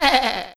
sheepHit.wav